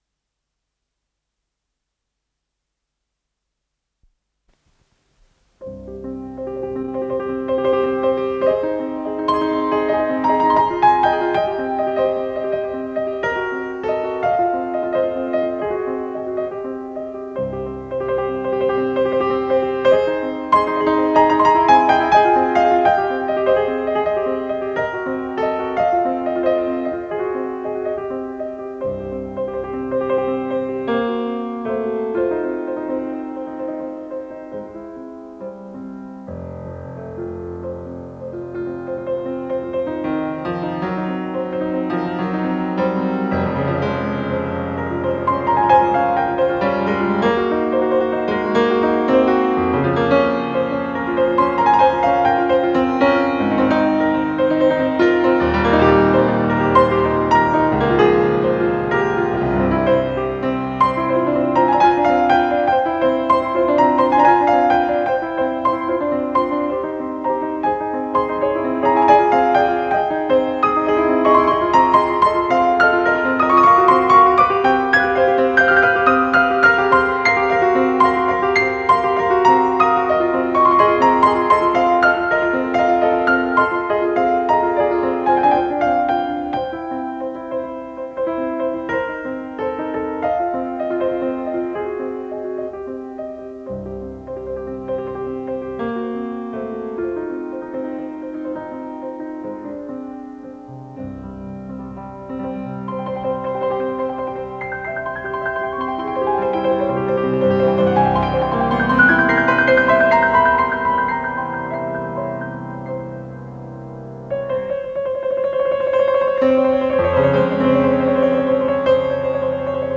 с профессиональной магнитной ленты
ПодзаголовокАллегро, для фортепиано, до мажор
ВариантДубль моно